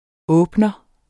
Udtale [ ˈɔːbnʌ ]